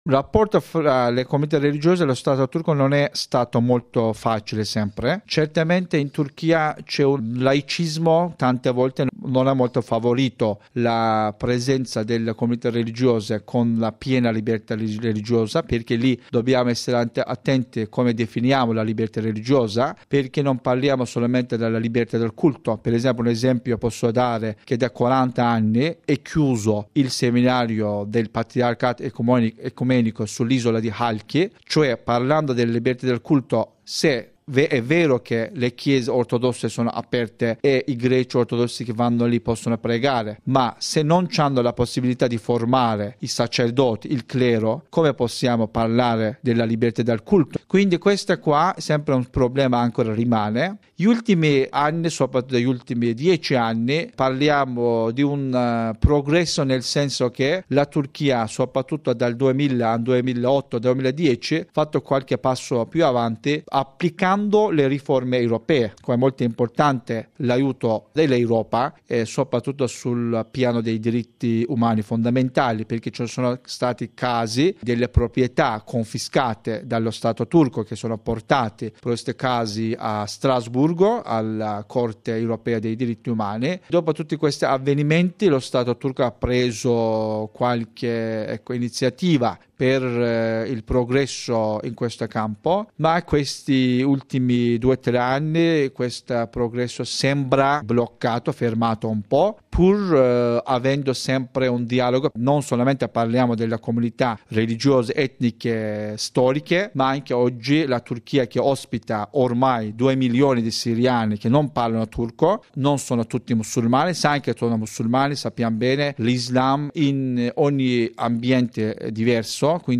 Turchia: rapporti tra Stato e religioni, intervista